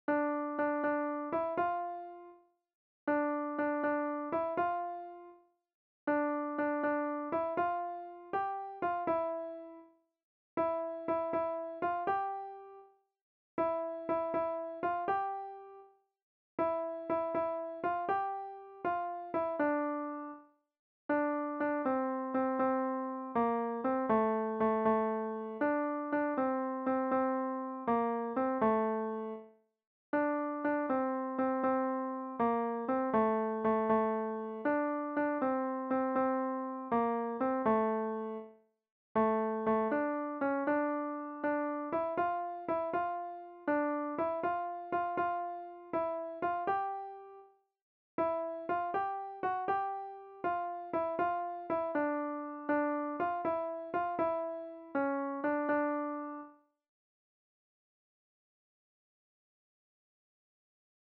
Kontakizunezkoa
Hamabiko handia (hg) / Sei puntuko handia (ip)
A-B-C-D